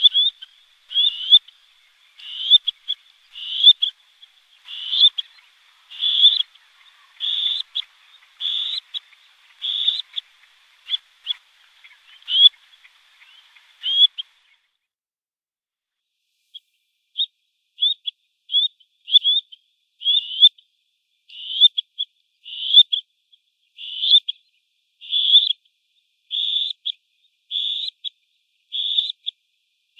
grebe-cou-noir-3.mp3